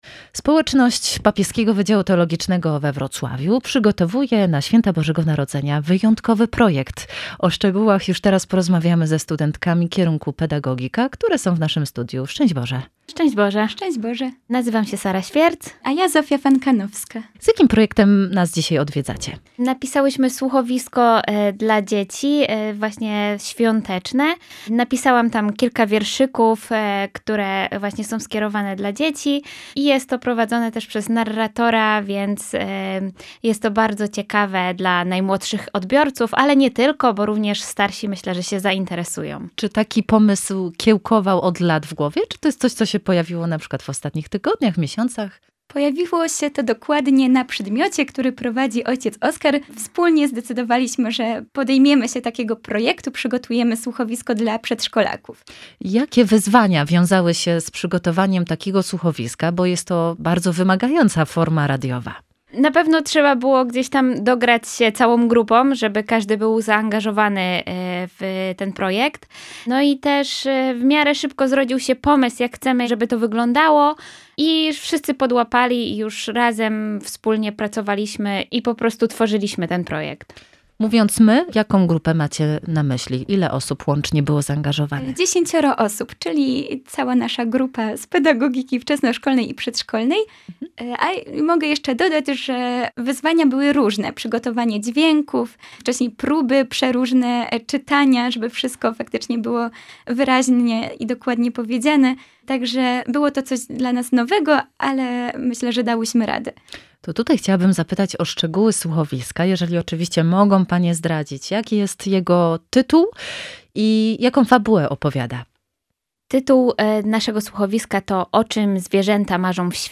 Autorki słuchowiska w RR
01_rozmowa.mp3